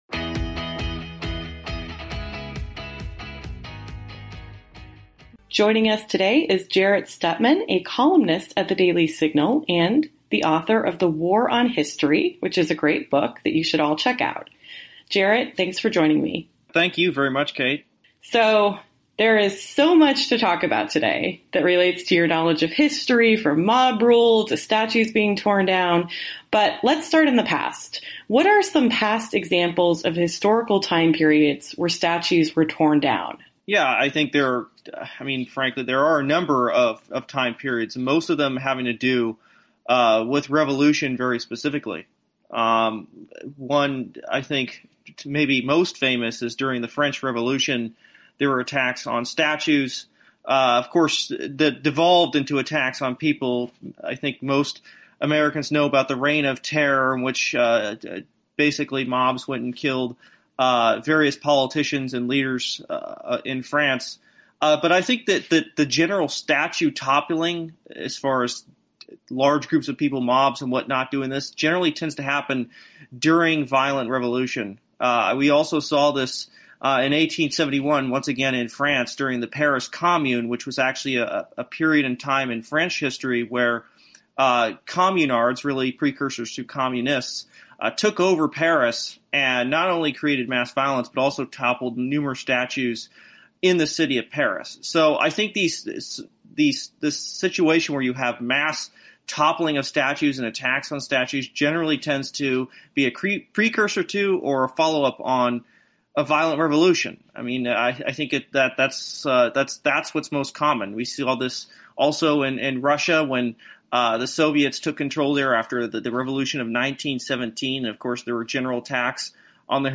Listen to the interview on the podcast, or read the lightly edited transcript, pasted below.